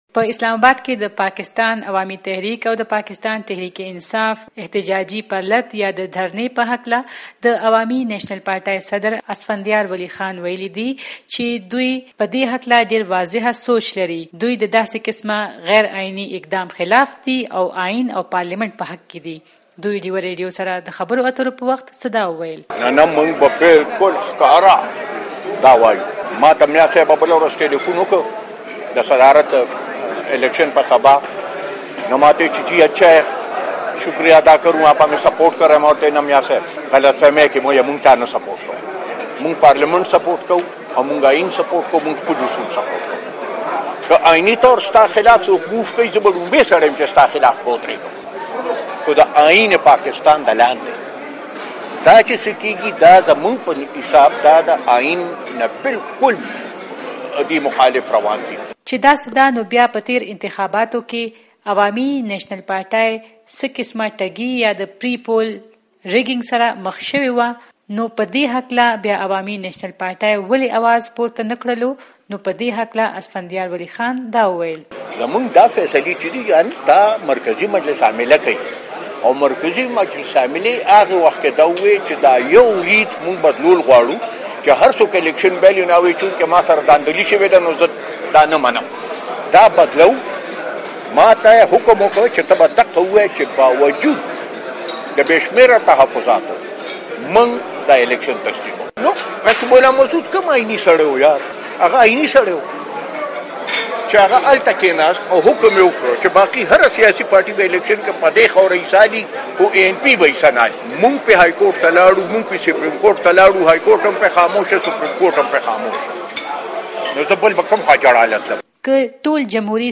د عوامي نيشنل پارټۍ مشر اسفنديار ولي خان نن د گل په ورځ پېښور کې د خبري غونډې په مهال وائس اف امريکا ډيوه ريډيو سره خبرو کې د عمران خان او طاهرالقادري د روانو دهرنو په اړه وئيل " دا چې څه کيږي دا زمونږ په حساب دويې د ائين نه باالکل مخالف روان دي " .
د این پې مشر اسفندیار ولي خان مرکه